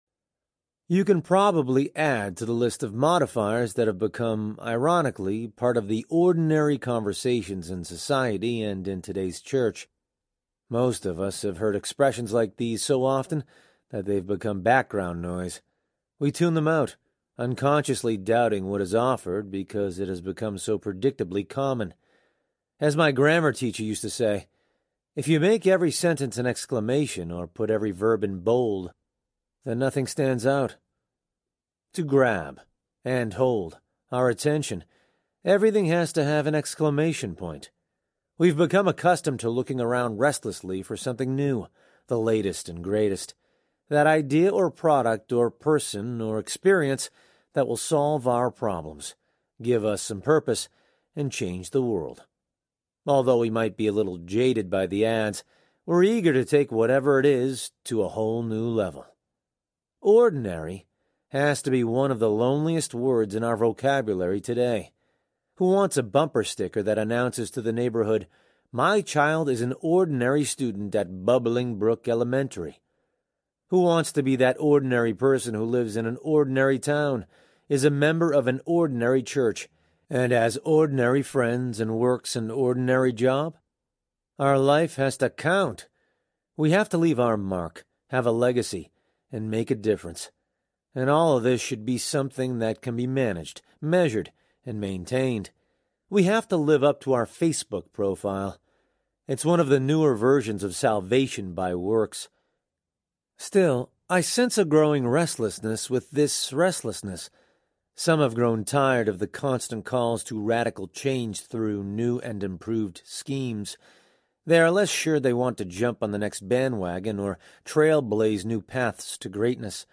Ordinary Audiobook
8.0 Hrs. – Unabridged